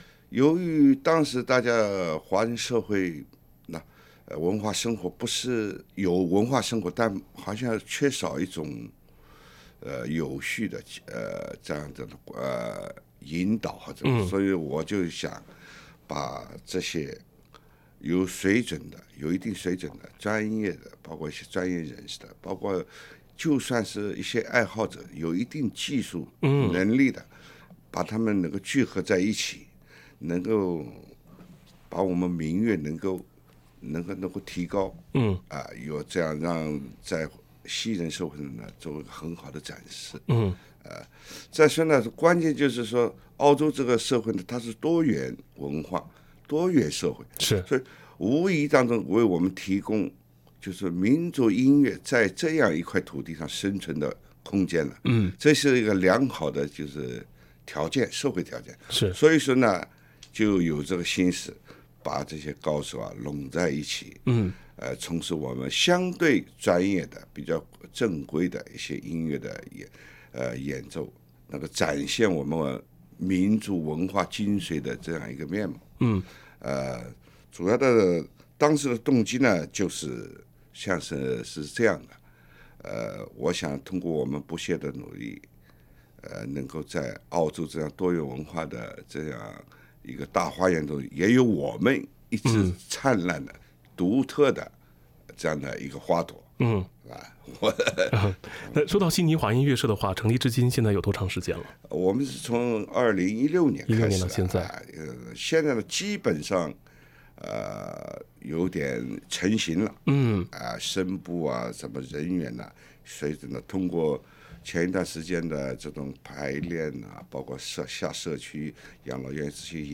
几位老师也把自己擅长的乐器带进直播间，演奏了一首中国民乐版本的澳大利亚知名乐曲《Old Man Emu》。
sydney_chinese_ensemble.mp3